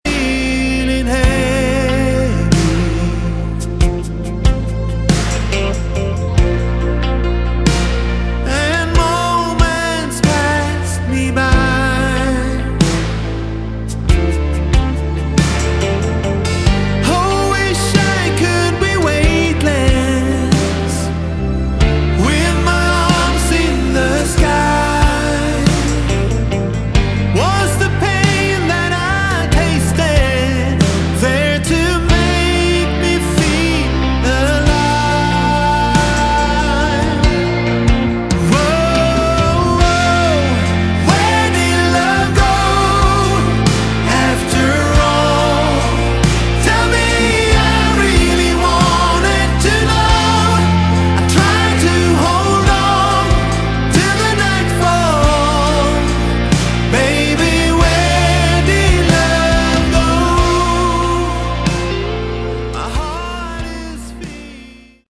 Category: AOR / Melodic Rock
Lead Vocals, Guitars
Guitars, Backing Vocals
Keyboards, Backing Vocals
Drums, Backing Vocals
Bass, Backing Vocals